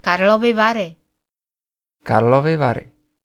ملف تاريخ الملف وصلات معلومات الصورة (ميتا) Cs-Karlovy_Vary.ogg  (Ogg Vorbis ملف صوت، الطول 3٫3ث، 210كيلوبيت لكل ثانية) Description Cs-Karlovy Vary.ogg English: Karlovy Vary (spa city in west Bohemia) pronounced in Czech by a speaker from Central Bohemia.